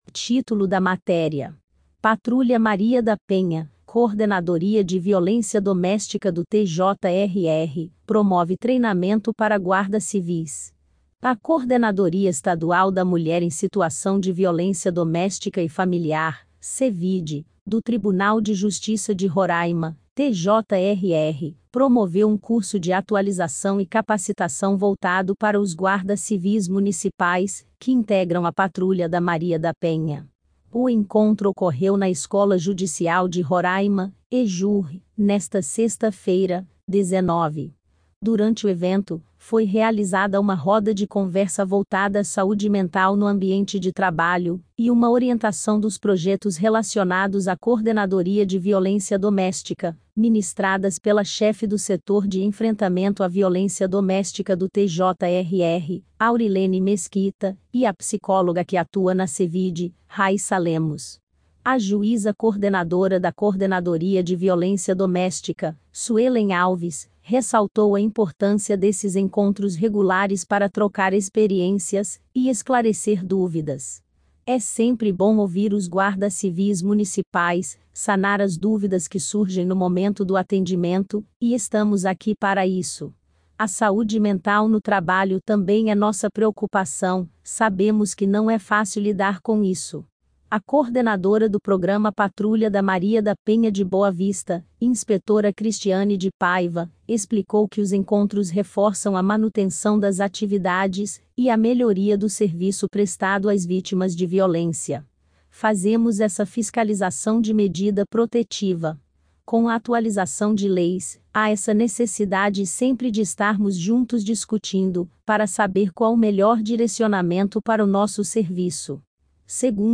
Acompanhe a matéria tambem em audio
PATRULHA_MARIA_DA_PENHA_IA.mp3